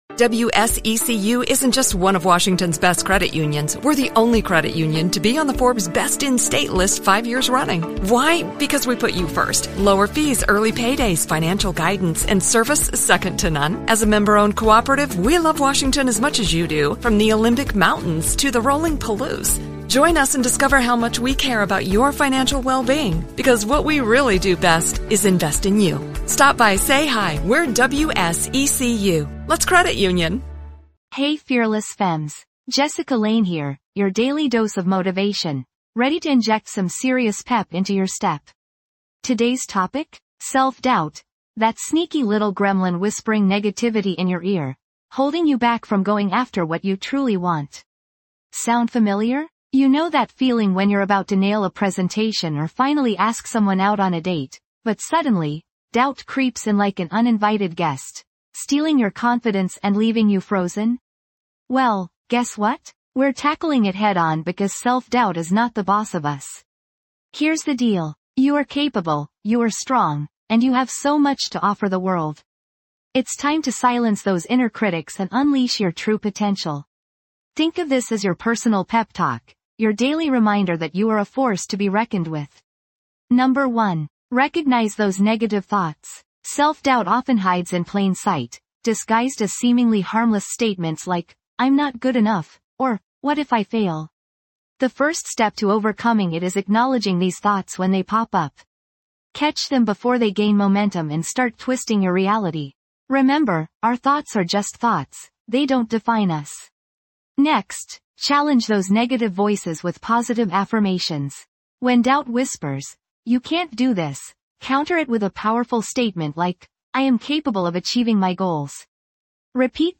Podcast Category:. Self-improvement, Motivation, Inspirational Talks
This podcast is created with the help of advanced AI to deliver thoughtful affirmations and positive messages just for you.